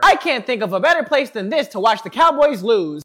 caramel express watch the cowboys lose 2 0 Meme Sound Effect